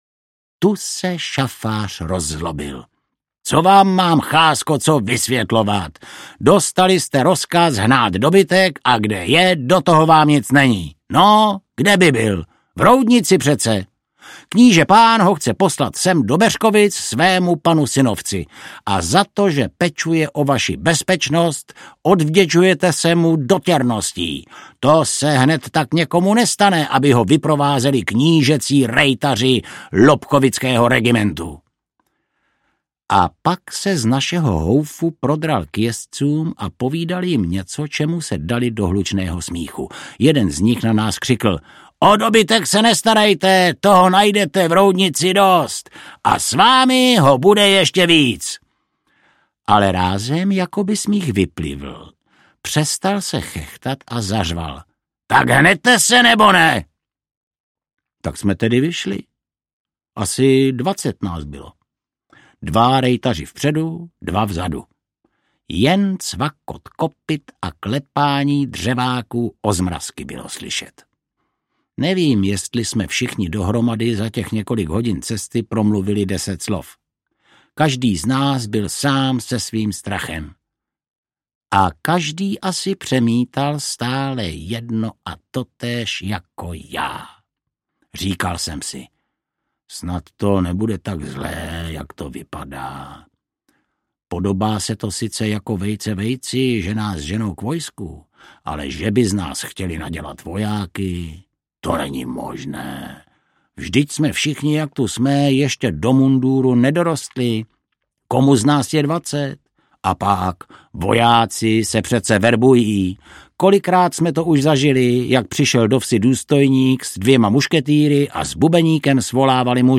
Podivuhodné příběhy a dobrodružství Jana Kornela audiokniha
Ukázka z knihy
Vyrobilo studio Soundguru.